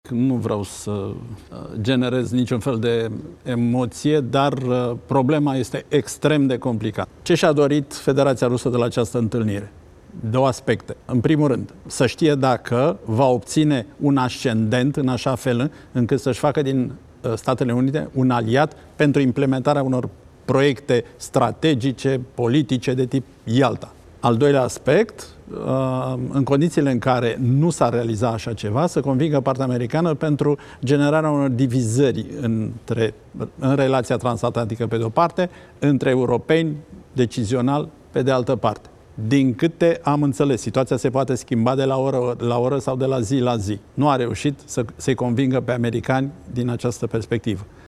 Consilierul prezidențial pentru apărare și siguranță națională, Cristian Diaconesu susține într-un interviu acordat postului Antena 3 că partea americană a „refuzat, în acest moment, dar nu avem garanții”.